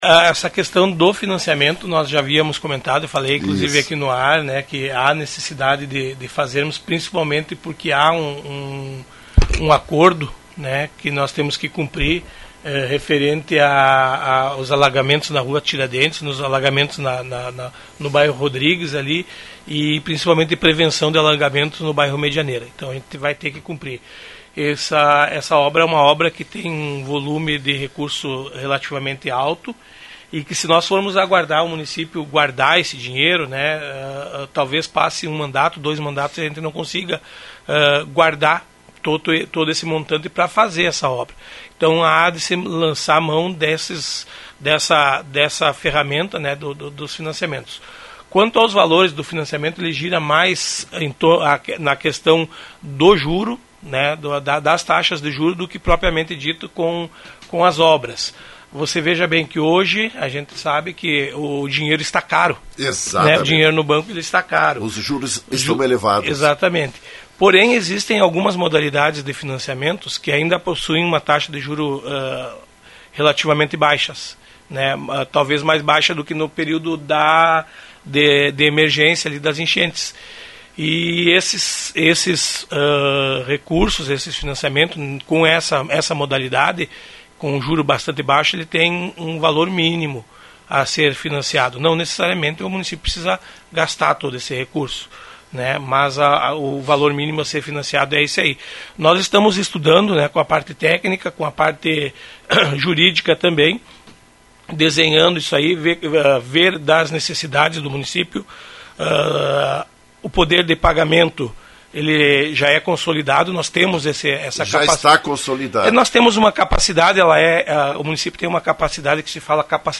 Prefeito de Lagoa Vermelha, Eloir Morona, confirmou, em entrevista à Rádio Lagoa FM, que o município trabalha a ideia de um financiamento bancário para resolver o problema dos alagamentos na cidade. São em três pontos: Rua Tiradentes, bairro Rodrigues e Medianeira.